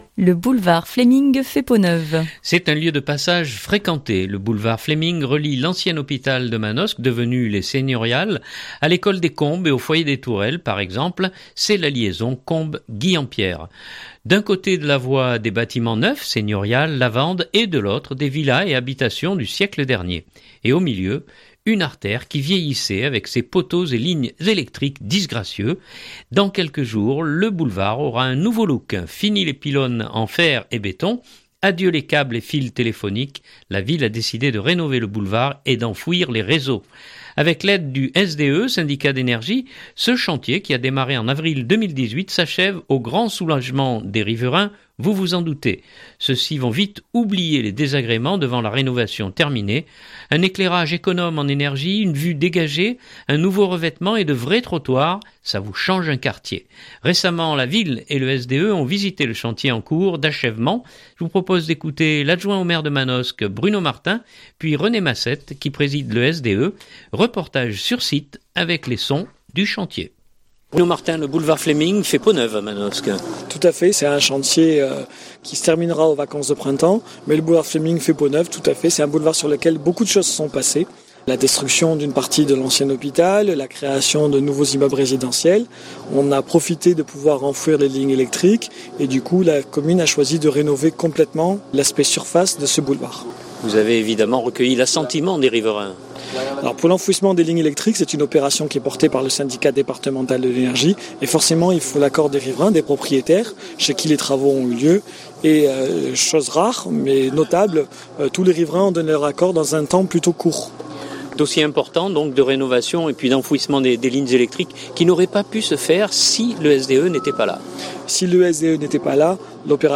reportage-Fleming-2019-03-20.mp3 (2.63 Mo)
Je vous propose d’écouter l’adjoint au maire de Manosque Bruno Martin puis René Massette qui préside le SDE. Reportage sur site avec les sons… du chantier.